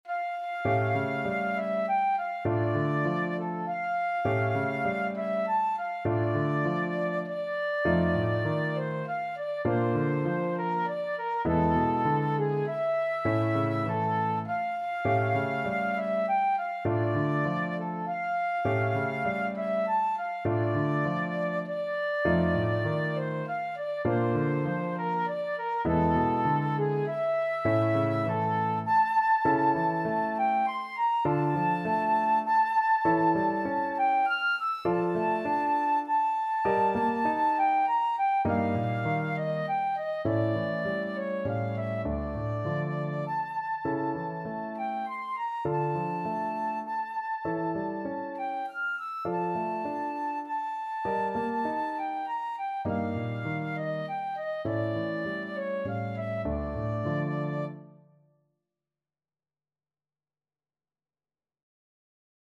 Flute
D minor (Sounding Pitch) (View more D minor Music for Flute )
3/4 (View more 3/4 Music)
Etwas bewegt
Classical (View more Classical Flute Music)